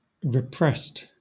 Ääntäminen
IPA : /ɹɪˈpɹɛst/